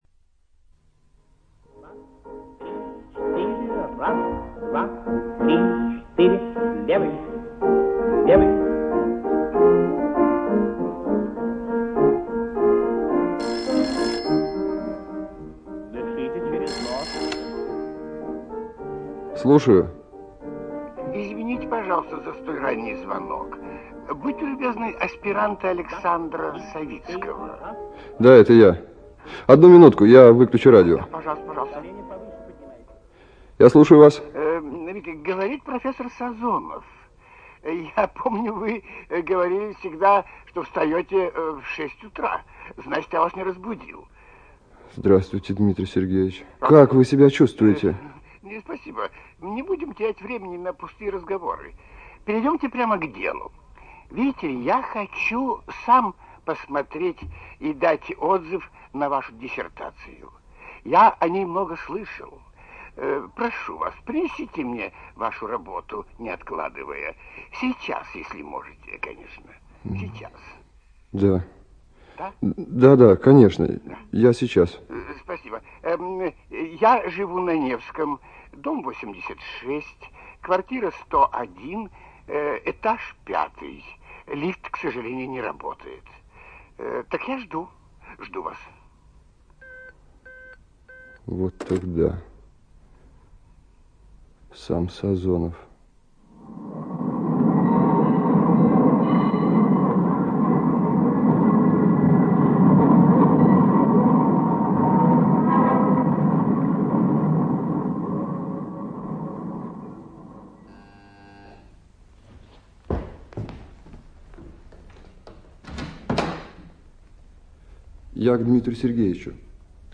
ЧитаютТараторкин Г., Волкова О.
ЖанрРадиоспектакли